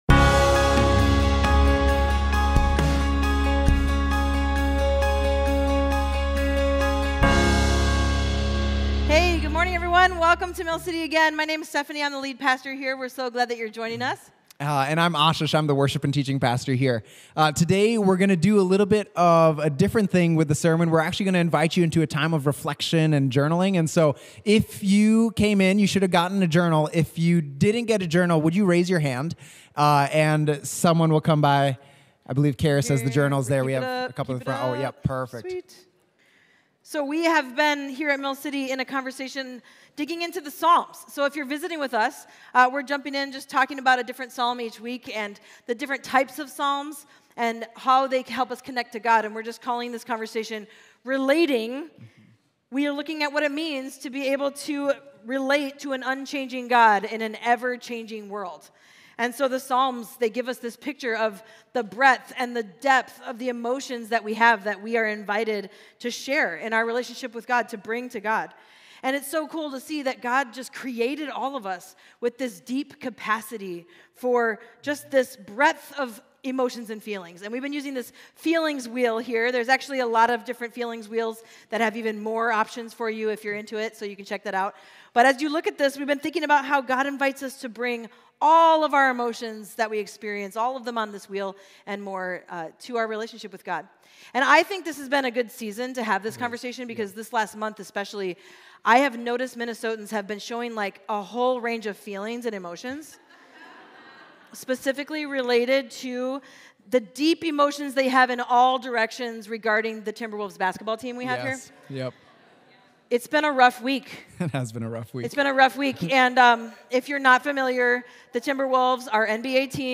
A conversation and reflection led by Pastors